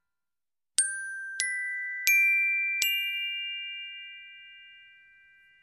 Звуки волшебства
• Качество: высокое
Эффект чуда